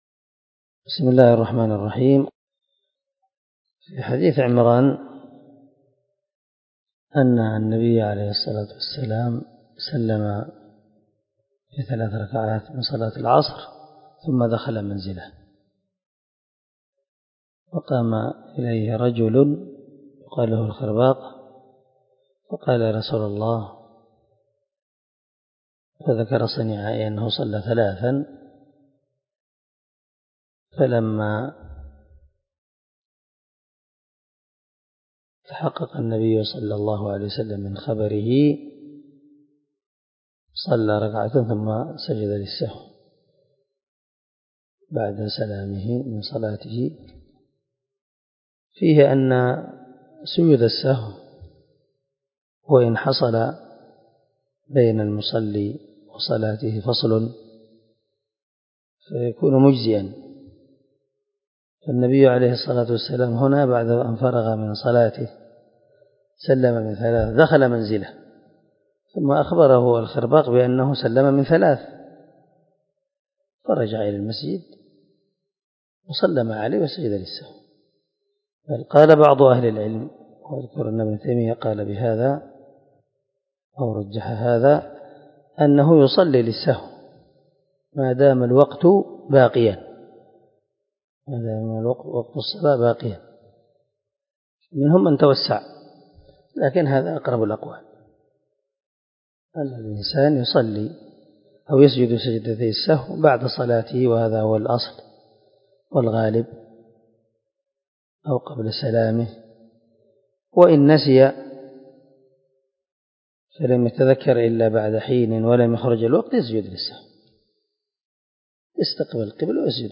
سلسلة_الدروس_العلمية
دار الحديث- المَحاوِلة- الصبي